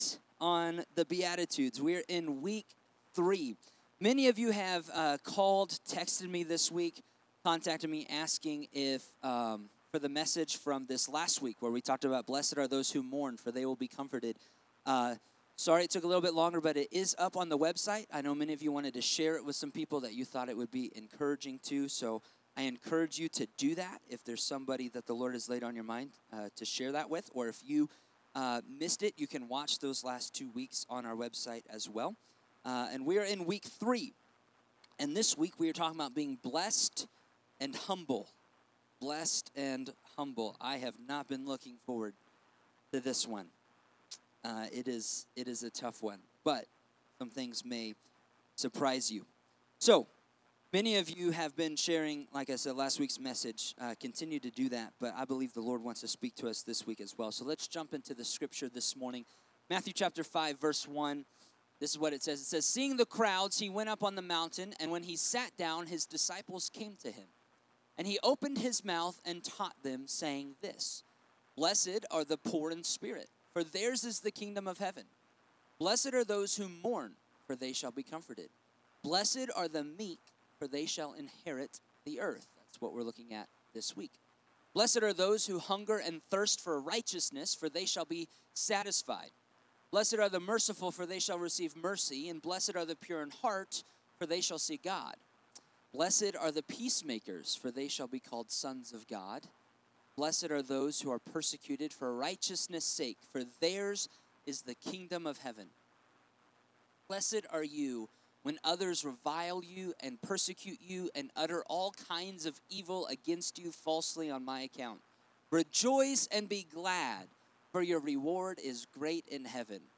Sermons | Mountain View Assembly